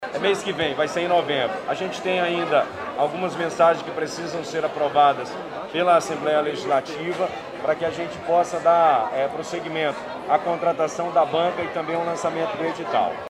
A informação foi dada em entrevista coletiva nesta sexta-feira (22), durante inauguração do Cerco Inteligente de Videomonitoramento, no Jorge Teixeira.